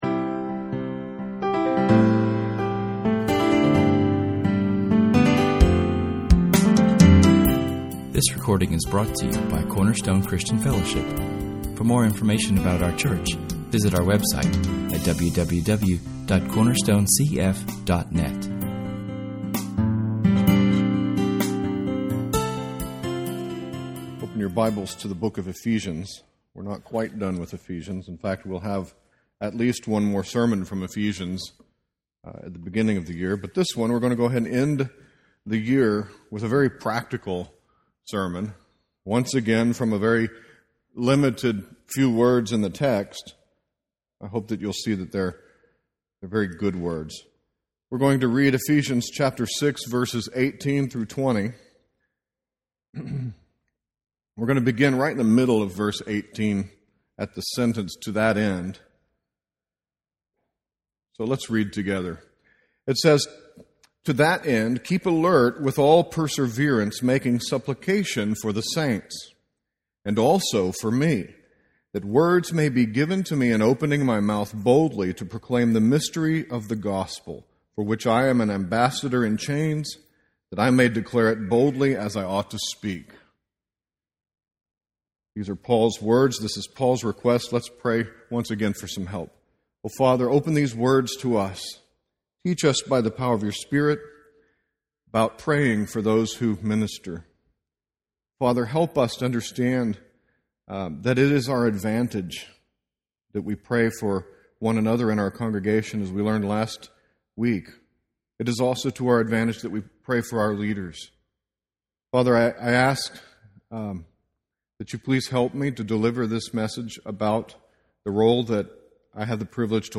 Our sermon includes how you can participate in your pastor’s ministry through prayer.